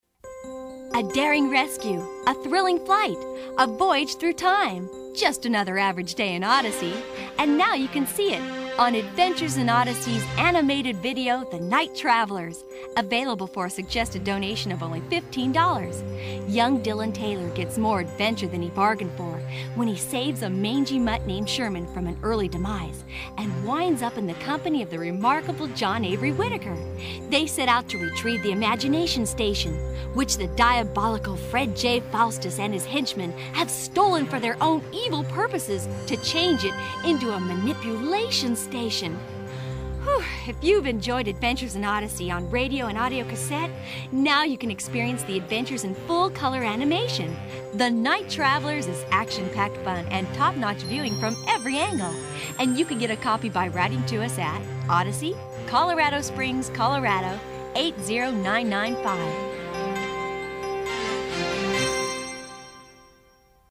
Original Series 1: "The Knight Travellers" Promo